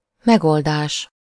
Ääntäminen
Ääntäminen US : IPA : [səˈluː.ʃən]